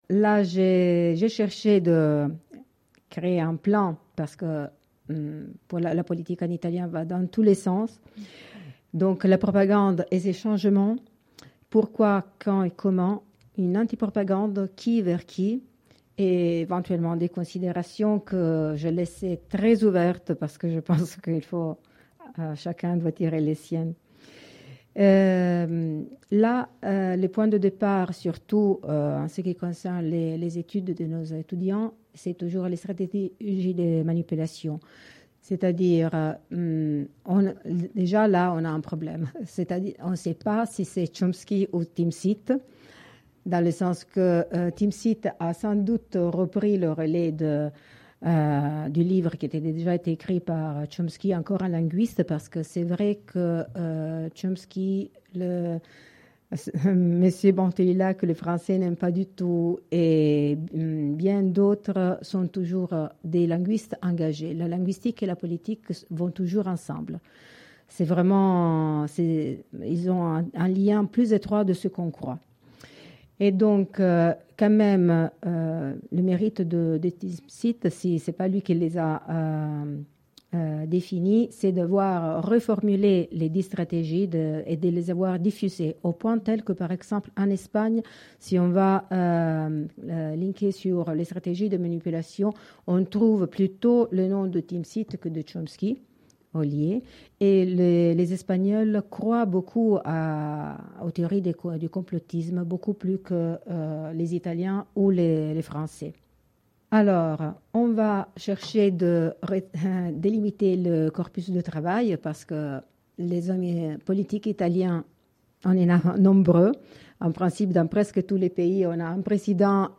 Cette conférence, donnée dans le cadre du programme PandheMic (Propagande : héritages et mutations contemporaines) , a été l'occasion d'attirer l’attention sur la transformation des stratégies de propagande et de spectacularisation de la politique italienne, à travers des slogans, des images parues sur le web (facebook, vidéo de propagande etc.) et le cinéma (Il divo, Viva la libertà, Il Caimano, Benvenuto Presidente, etc.). Elle fut l'occasion de livrer des éléments pour apprendre à lire un des scénarios politiques les plus surprenants, de mieux reconnaître les stratégies de manipulation grâce à une forte spectacularisation et pousser vers une prise de conscience.